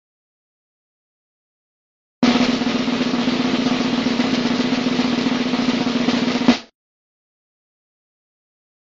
Drum Gabo Efecto de Sonido Descargar
Drum Gabo Botón de Sonido